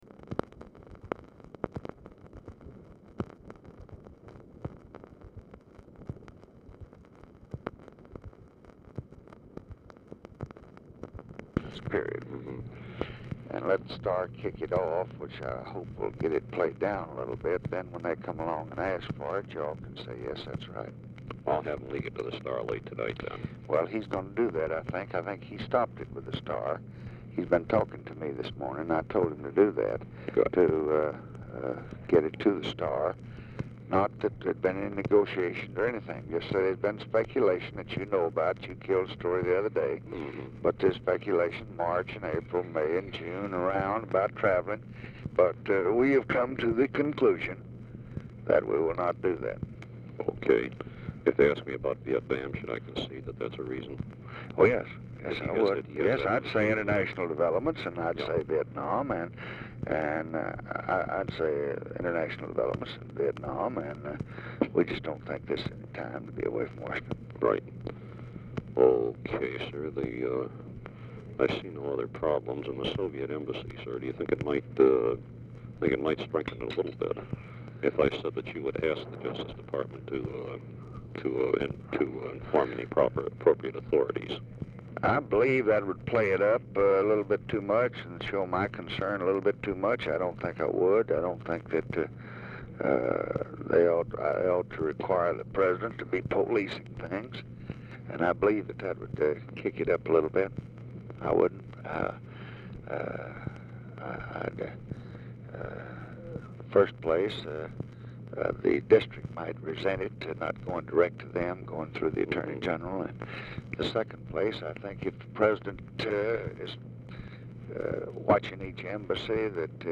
Telephone conversation # 7037, sound recording, LBJ and GEORGE REEDY, 3/8/1965, 11:00AM | Discover LBJ
Format Dictation belt
Location Of Speaker 1 Mansion, White House, Washington, DC
Specific Item Type Telephone conversation